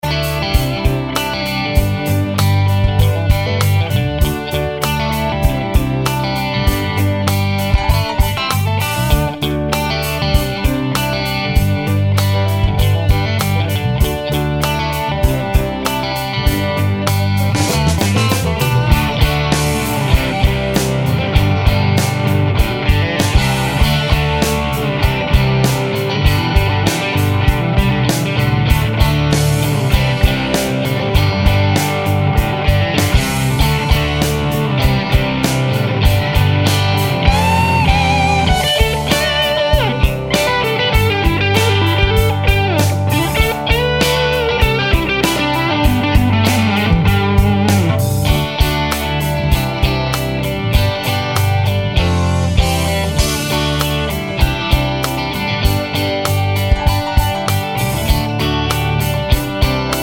no Backing Vocals Rock 4:34 Buy £1.50